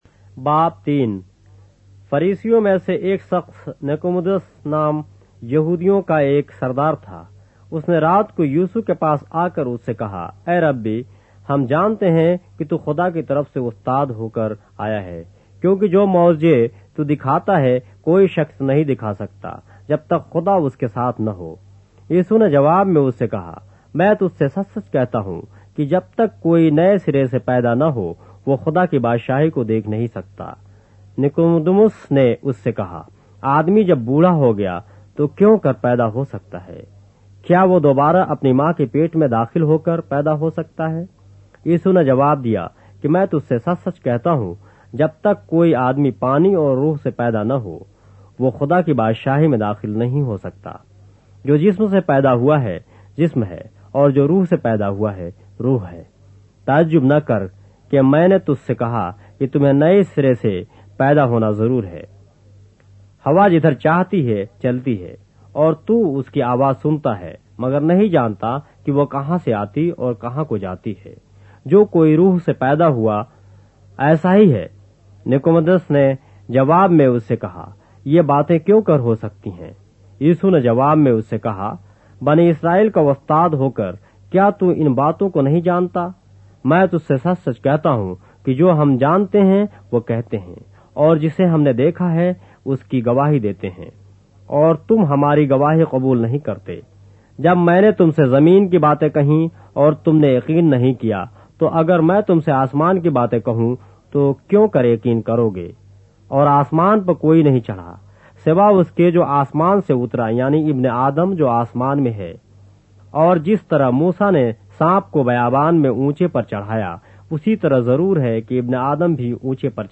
اردو بائبل کے باب - آڈیو روایت کے ساتھ - John, chapter 3 of the Holy Bible in Urdu